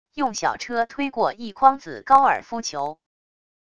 用小车推过一筐子高尔夫球wav音频